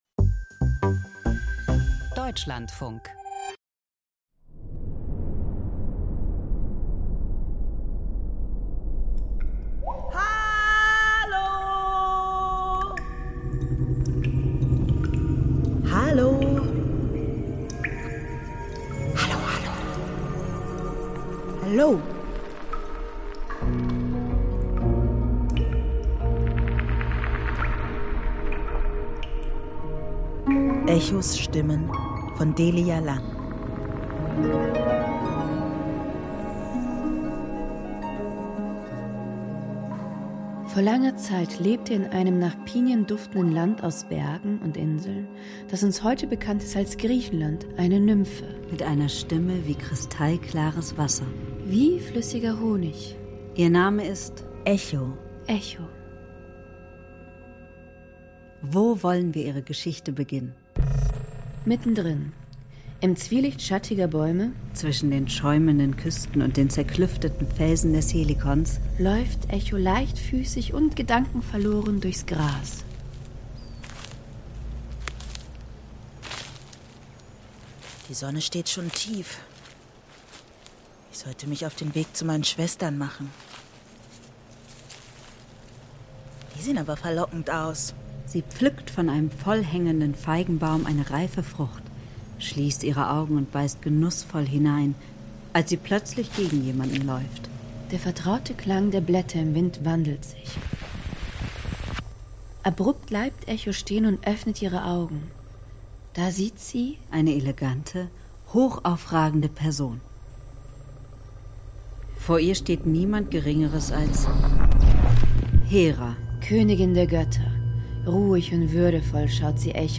Hörspiel über Echo und Narziss - Echos Stimmen
Ein Kurzhörspiel über Sprache, Begehren und die Macht, alten Mythen völlig neuen Raum zu geben.